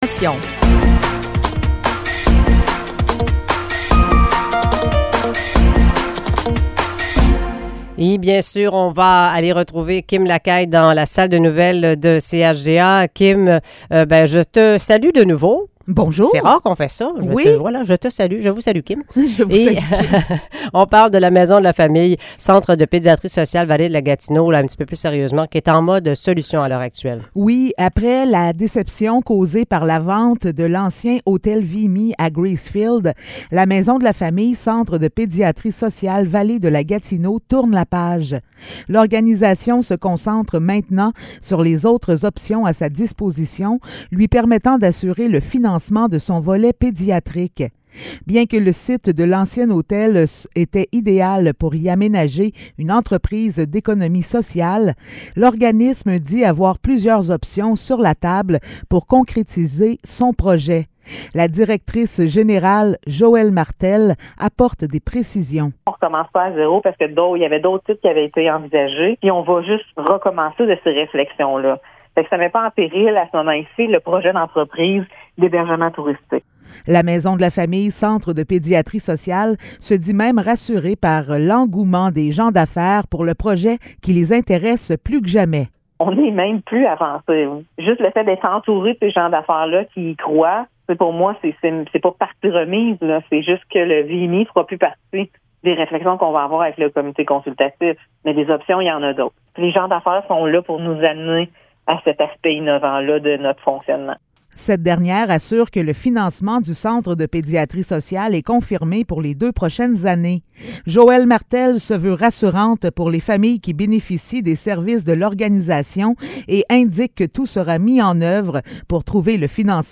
Nouvelles locales - 27 avril 2023 - 7 h